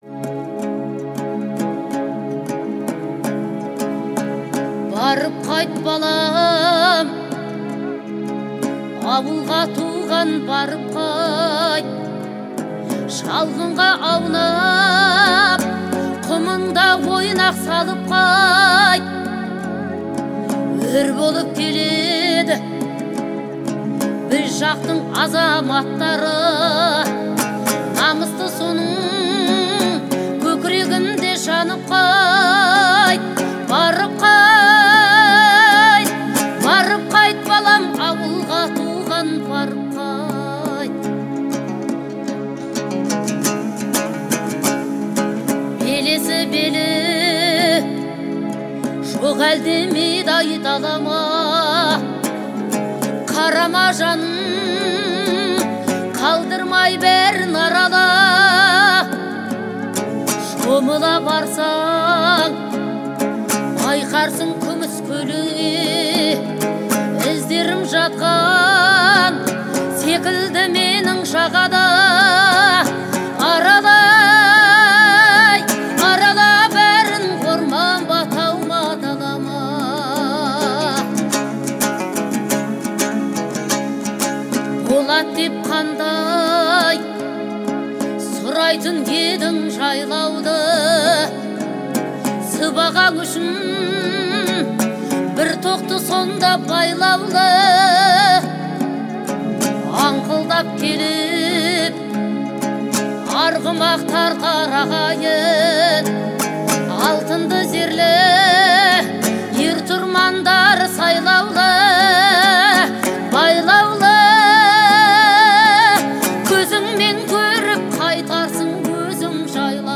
это трогательная песня в жанре казахской народной музыки